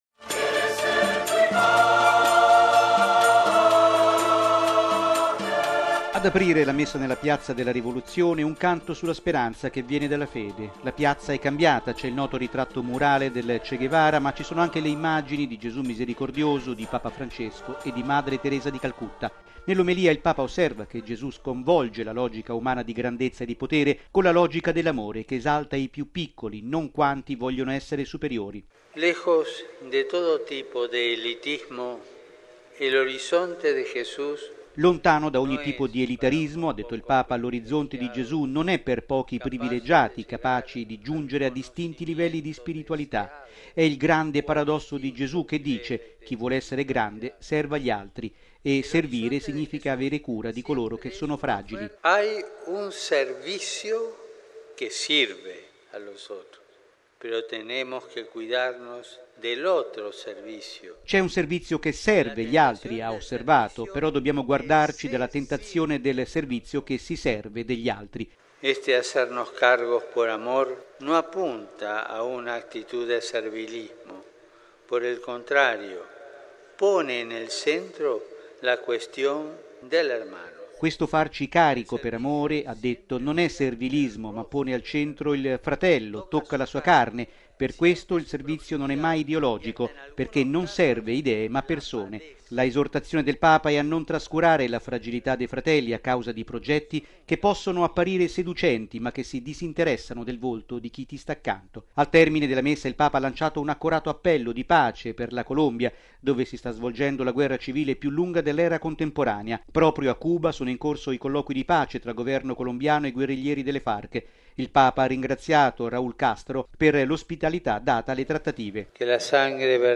Hanno accompagnato la liturgia canti gioiosi tipici della spiritualità cubana.